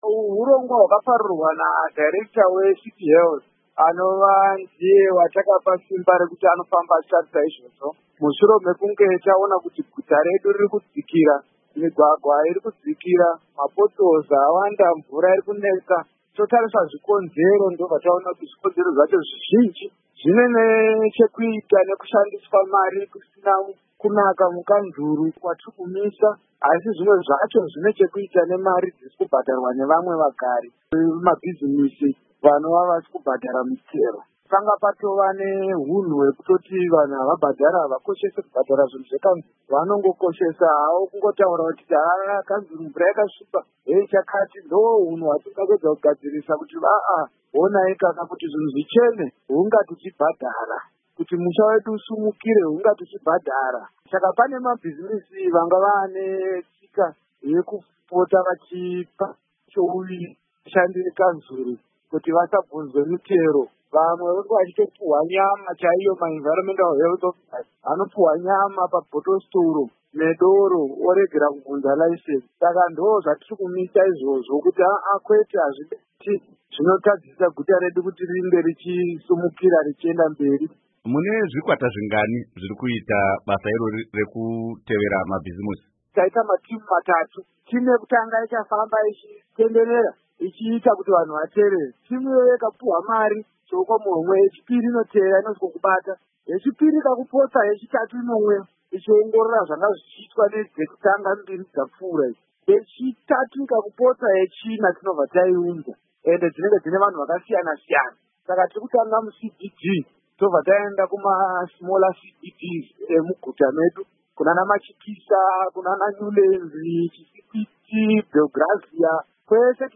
Hurukuro naVaHerbert Gomba